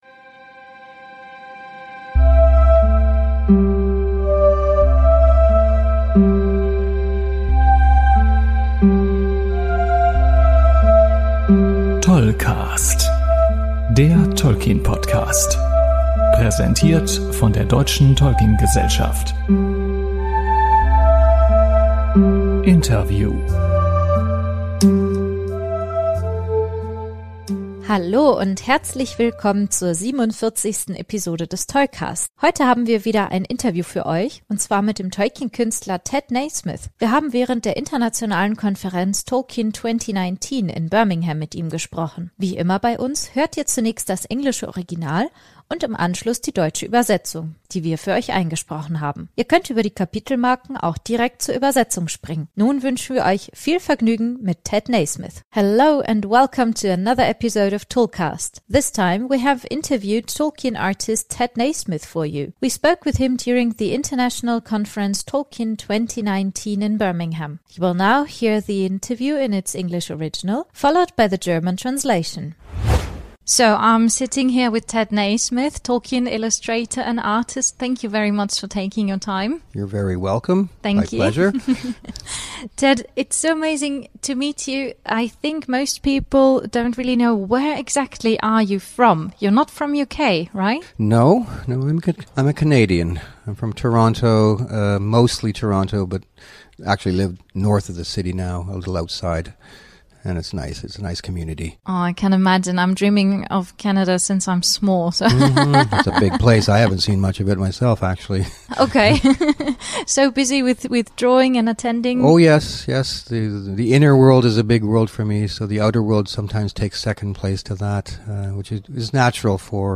Wie immer beim TolkCast hört Ihr zunächst das englische Original und im Anschluss die von uns eingesprochene Übersetzung ins Deutsche.